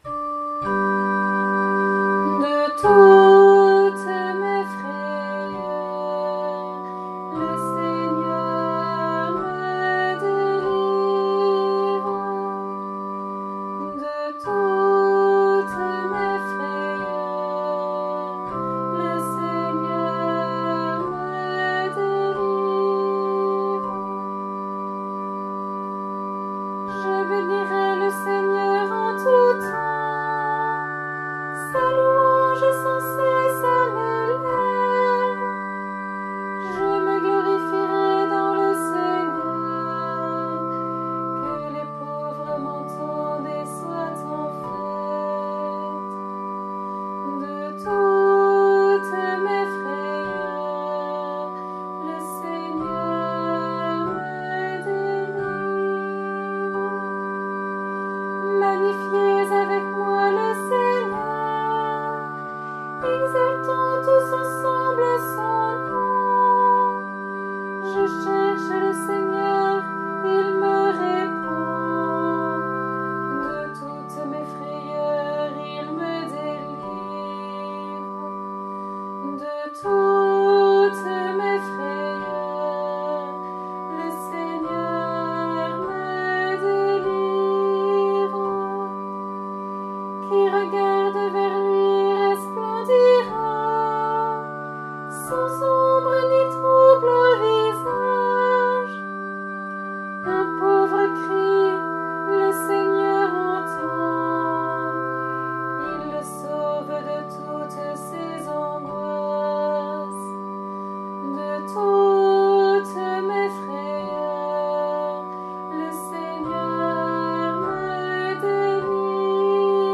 Antienne pour la solennité de Saint Pierre et Saint Paul